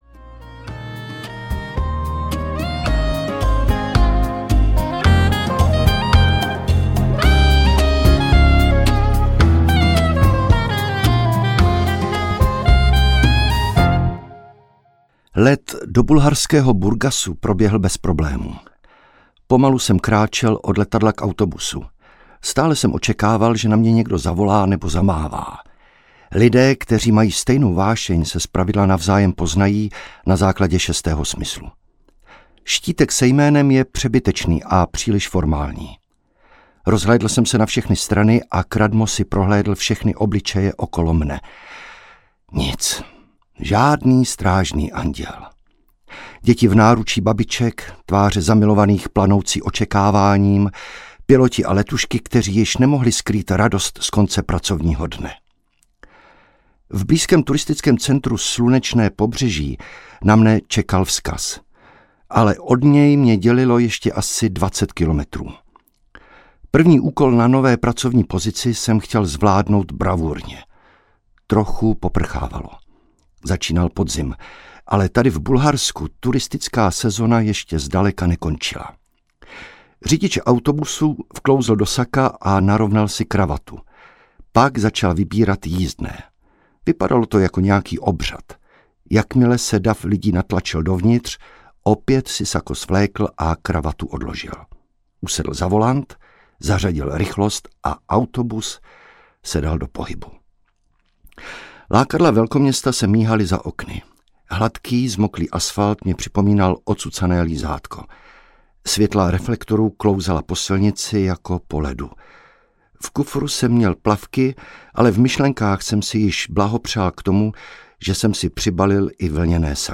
Ukázka z knihy
kral-v-nesnazich-audiokniha